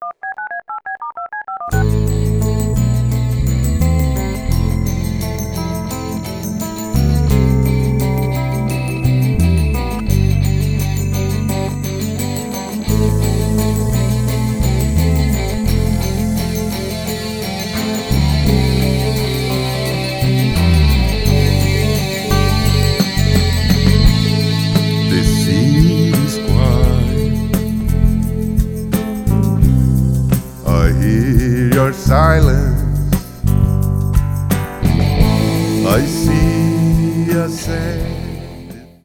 Baixo/Percussão/Bateria/Guitarra